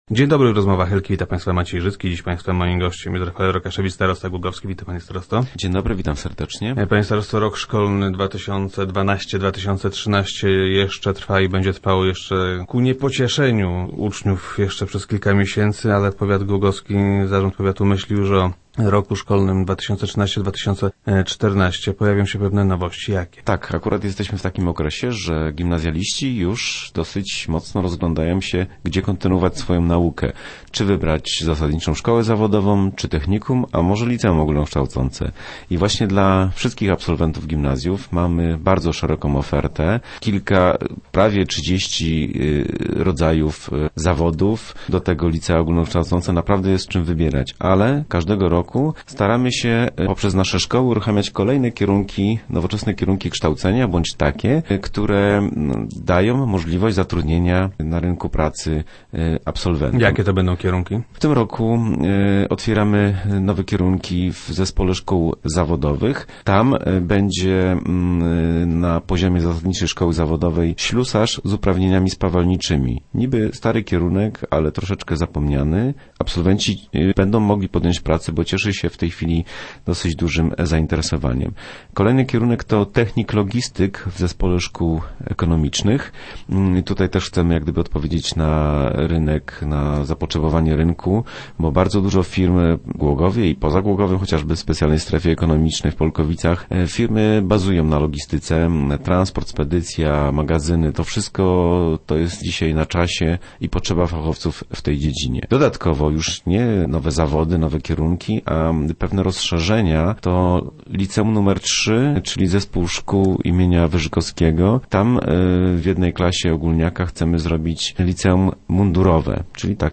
Pomysłów na ponadgimnazjalną oświatę jest sporo. - Nie jest łatwo, bo musimy radzić sobie z demograficznym niżem – twierdzi starosta Rafael Rokaszewicz, który był gościem Rozmów Elki.